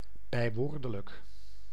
Ääntäminen
Ääntäminen France: IPA: [ad.vɛʁ.bjal] Haettu sana löytyi näillä lähdekielillä: ranska Käännös Konteksti Ääninäyte Adjektiivit 1. bijwoordelijk kielioppi Suku: m .